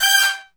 G5 POP FALL.wav